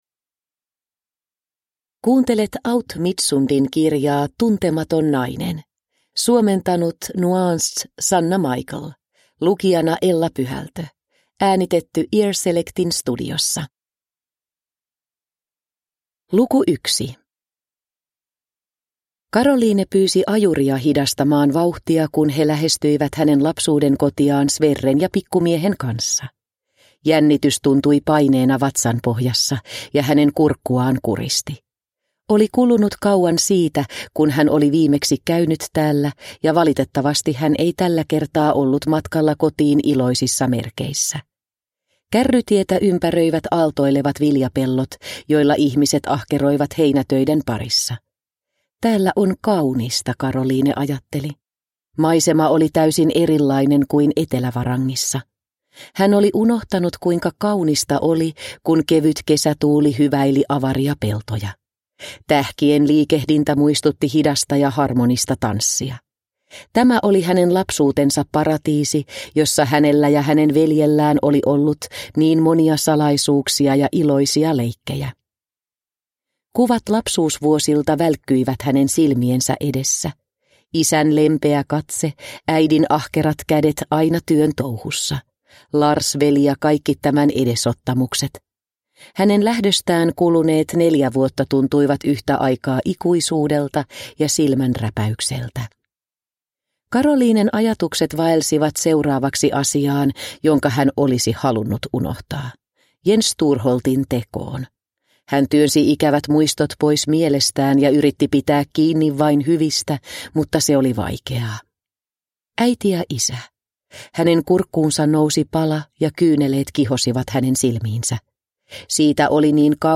Tuntematon nainen – Ljudbok – Laddas ner